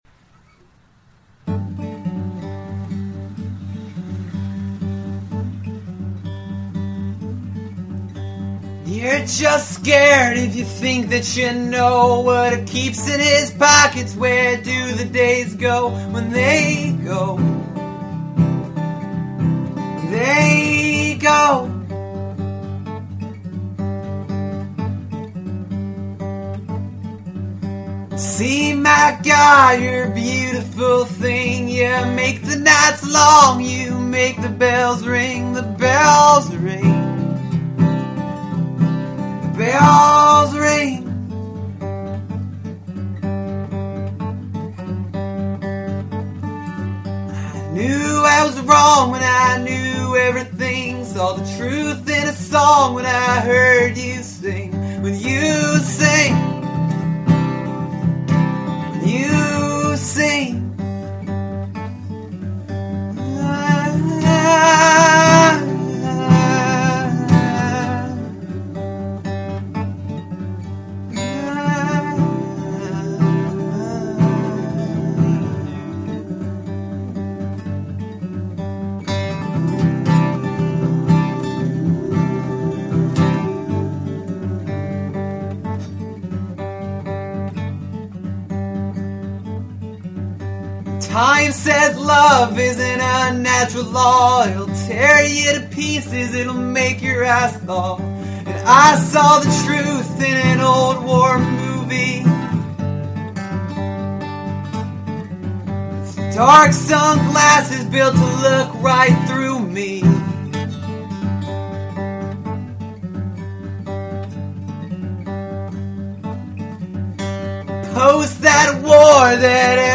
dark Americana tinged singer songwriter projects